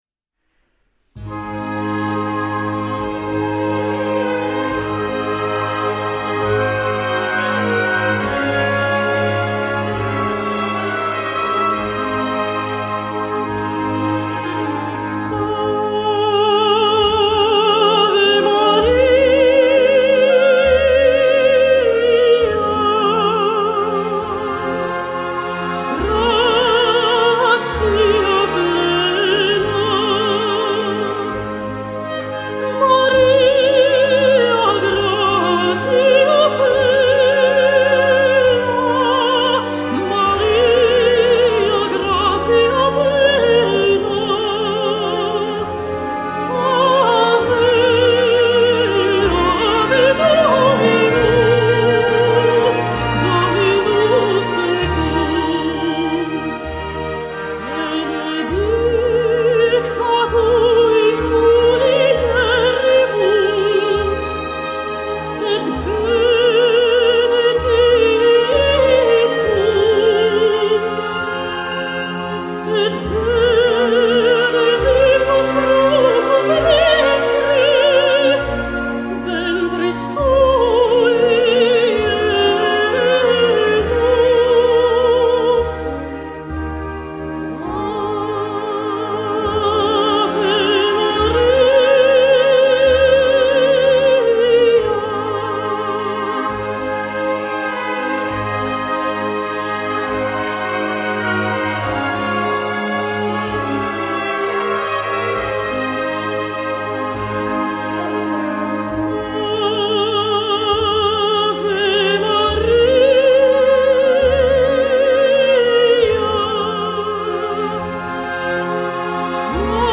für Posaune und Klavier